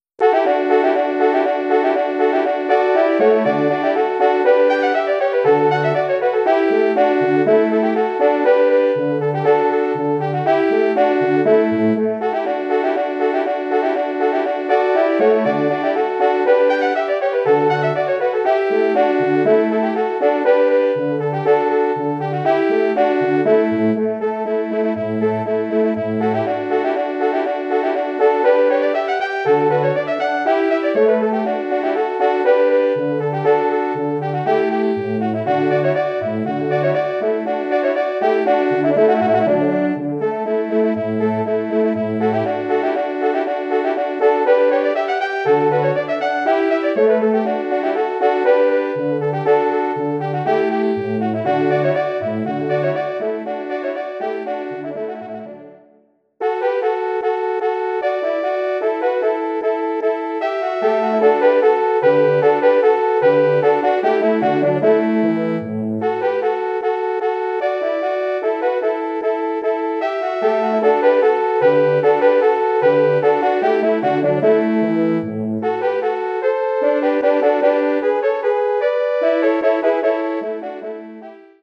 Arrangement / Hornquartett
Besetzung: 4 Hörner
Instrumentation: 4 horns